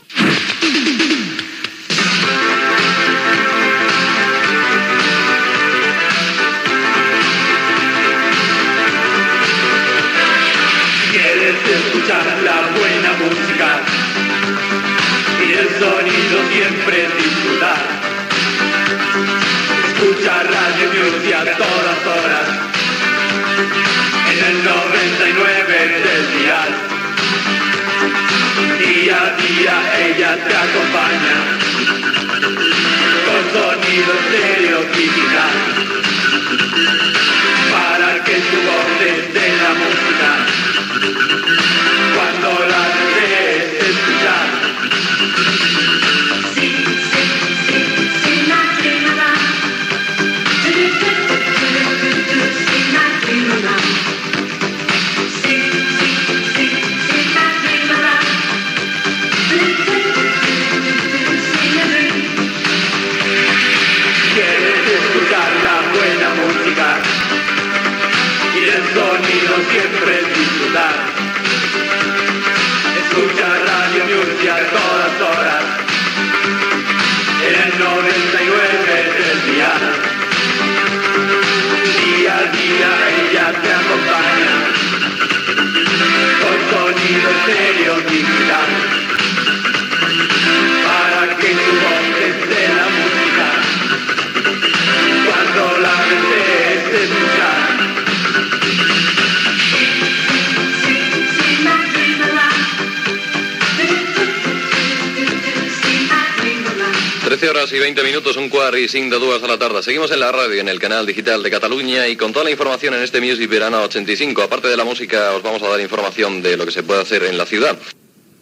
Cançó de la ràdio, hora, nom del programa i comentari